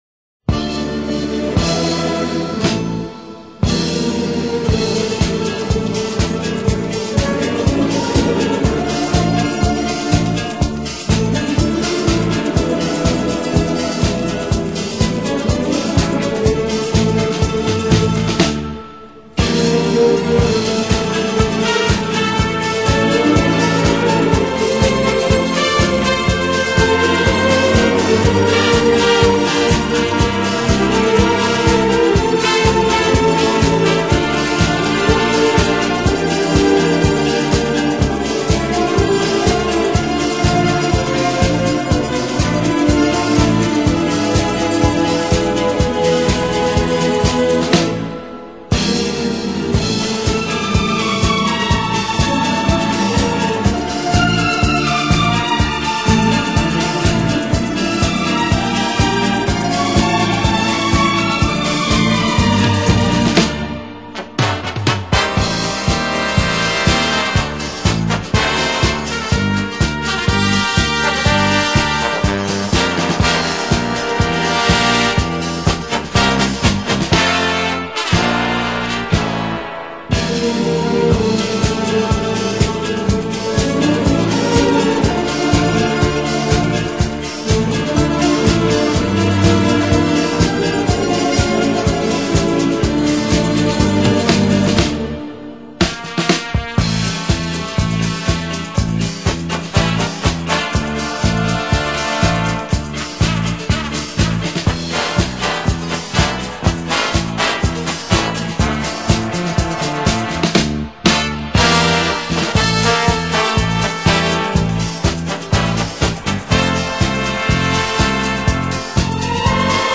Instrumentais Para Ouvir: Clik na Musica.